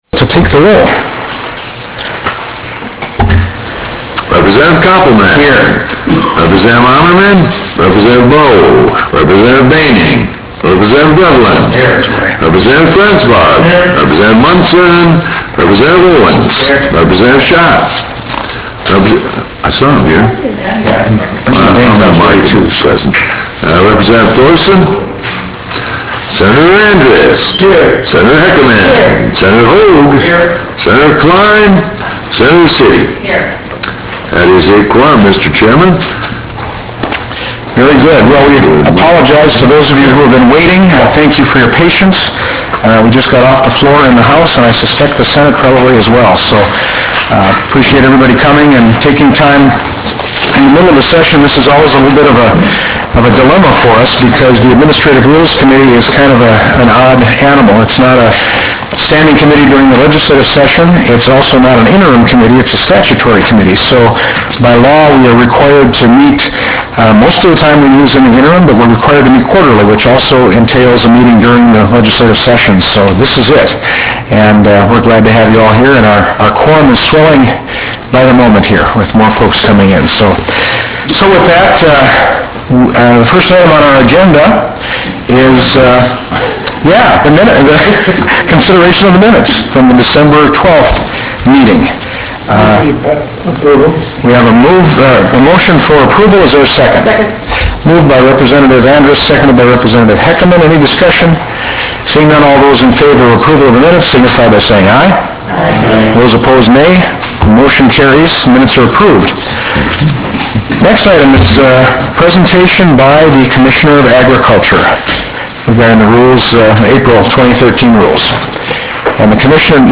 Prairie Room State Capitol Bismarck, ND United States